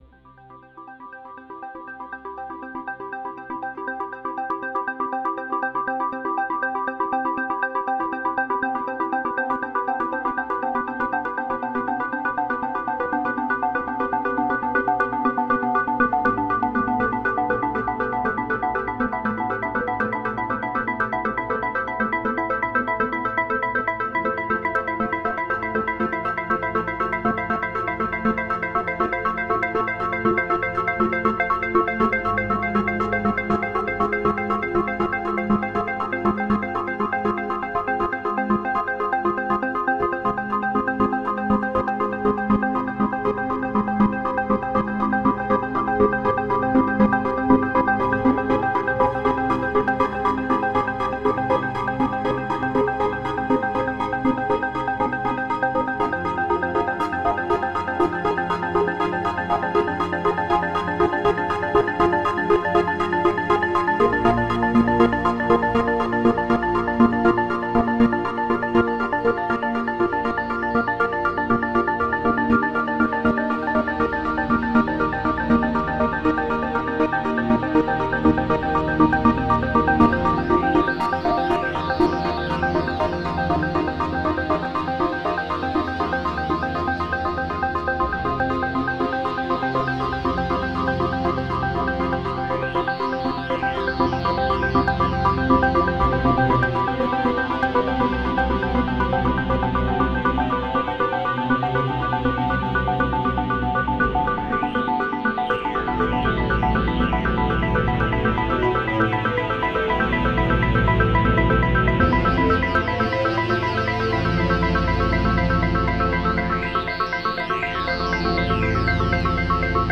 Soundscapes and images inspired (at least in part) by avant garde composers of the last century, including Karheinz Stockhausen and György Ligeti.
There is chordal progression in this piece, but it is disguised by substituting a continuous cross-fade for the more usual rhythmic transition.
The intention was to induce in the listener a curious but not uncomfortable sense of displacement. One is aware that the music is changing, but one may not be able to describe exactly how it is changing.